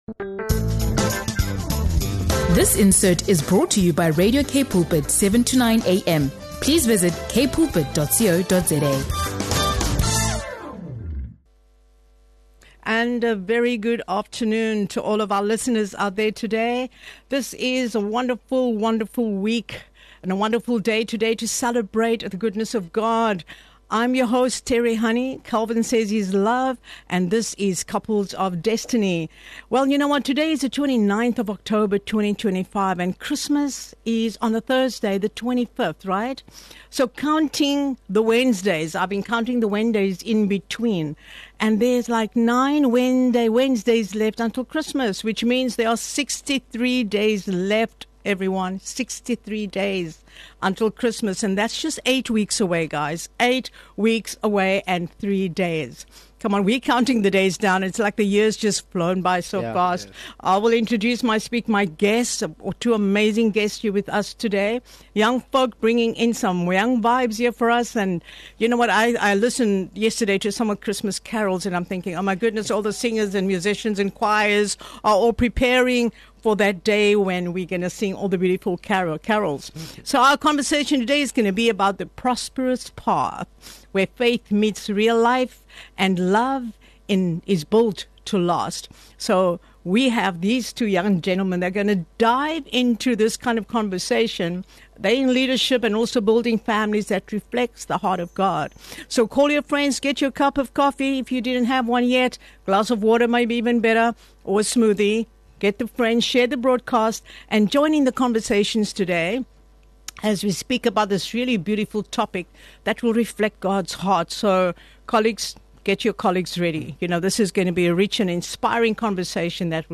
In this inspiring Young Couples Conversation, they share biblical wisdom on marriage, faith, family, and leadership — offering practical guidance for couples who want to build relationships rooted in God’s purpose. Discover how to strengthen your marriage, lead with love, and live with faith in every season.